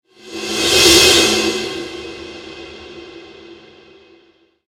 Metallic Whoosh Sound Effect: Shiny Cymbal Transition
Description: Metallic whoosh sound effect. Cymbal crash long decay. Metallic transitional shiny cymbal sound with long sustain.
Genres: Sound Effects
Metallic-whoosh-sound-effect.mp3